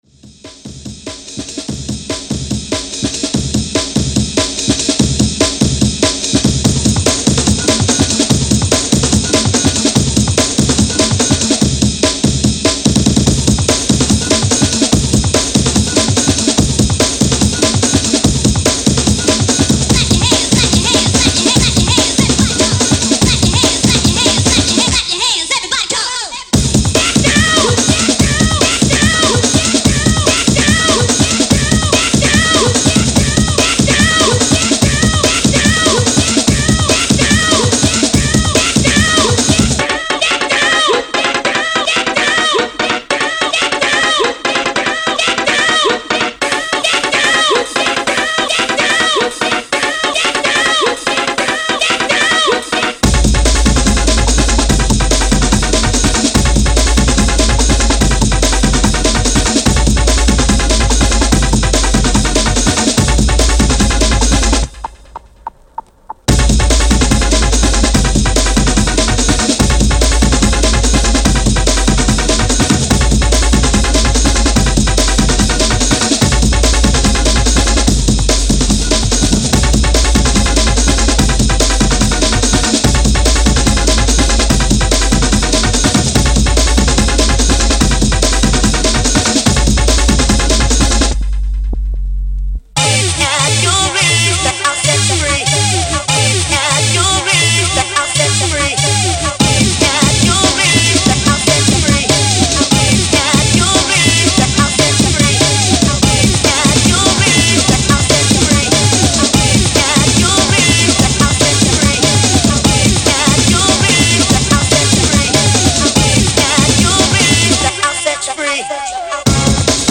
90s Jungle